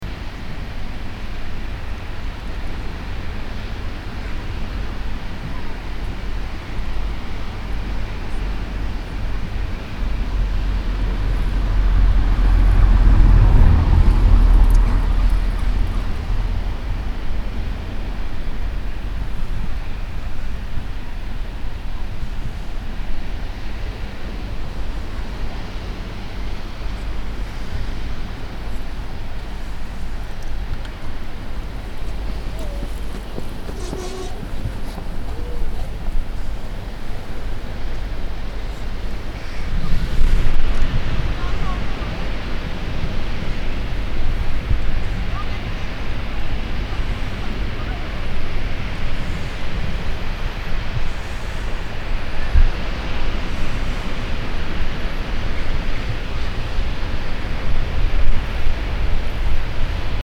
I've also made new sounds, that being a misnomer in a general interpretation, by recording the North Sea!
1 AT2020 in front of the front window, another Audio Technica in front of the open rear window, a little from the opening and with an amount of foam around a part of the mic´s head to prevent wind noise.
1 min result, pretty loud low frequencies can be heard at times from traffic, but a nice stereo (distant) sea sound, even throught the mp3: